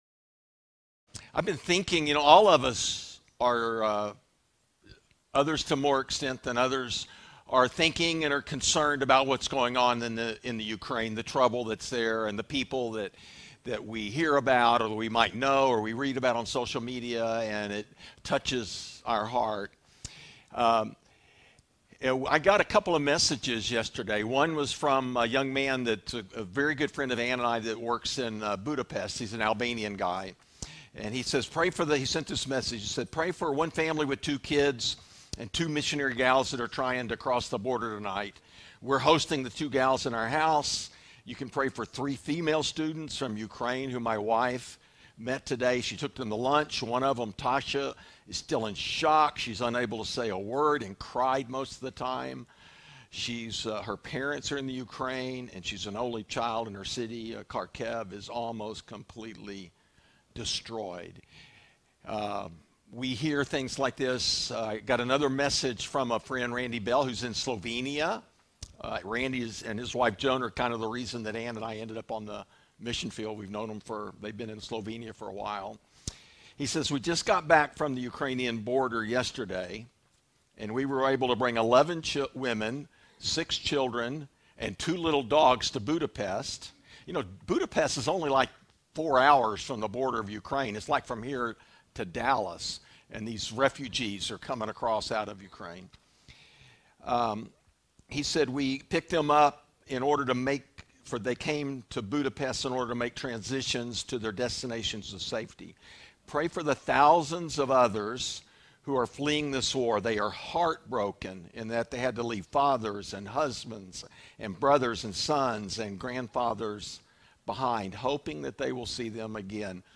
preaches on Revelation 5